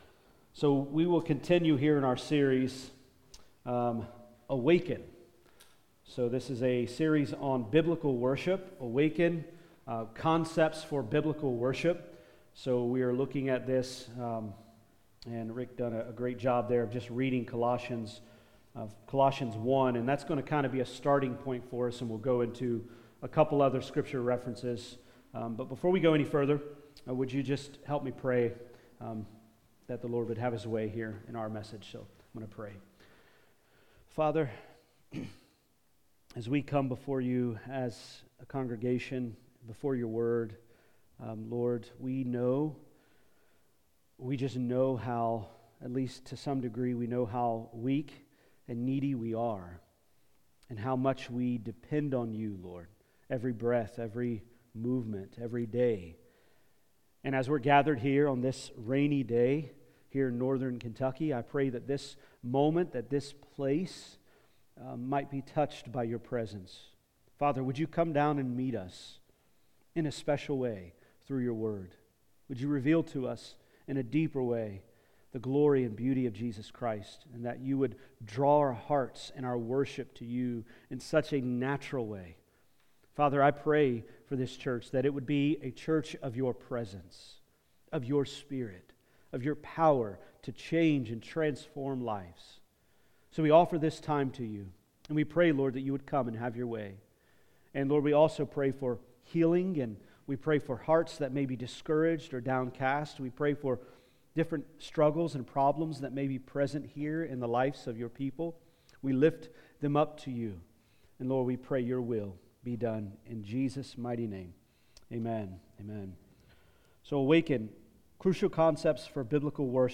Sermons | Florence Alliance Church